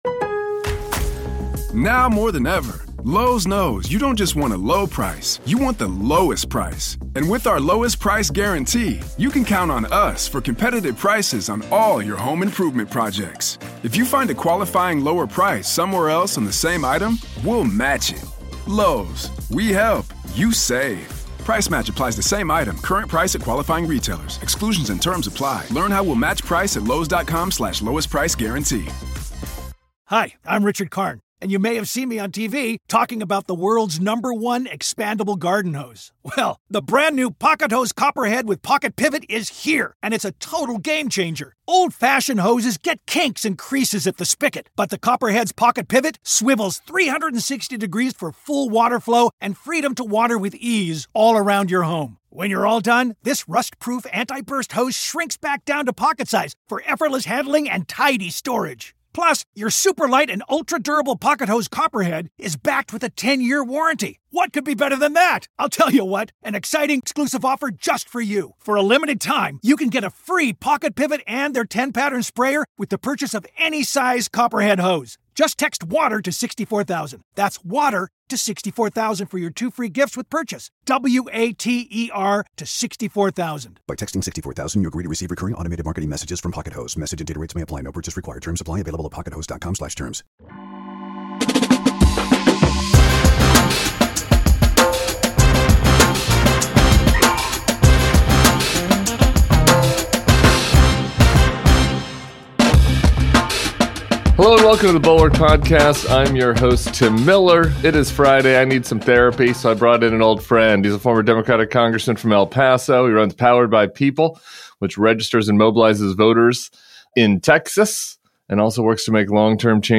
Plus, RFK's dangerous proposed autism registry is selling a cruel fantasy. And Democrats need to lock down what they're selling and listen to what voters want from the government. Beto O'Rourke joins Tim Miller for the weekend pod.